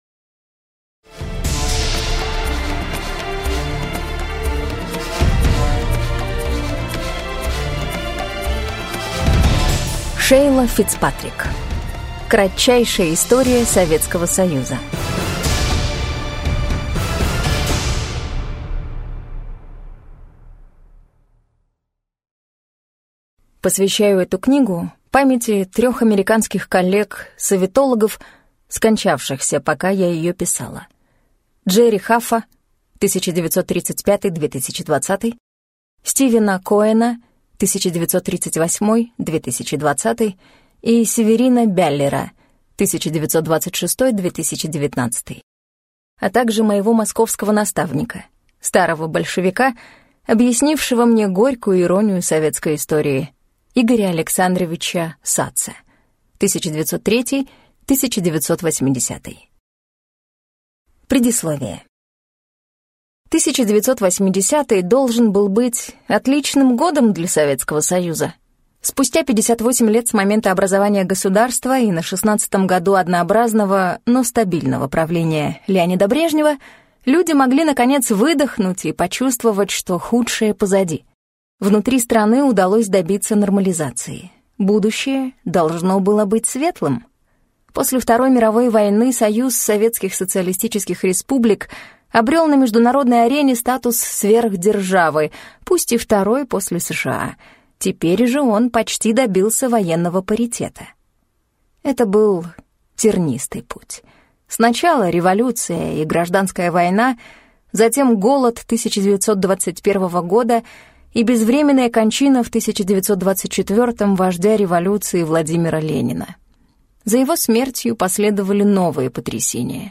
Аудиокнига Кратчайшая история Советского Союза | Библиотека аудиокниг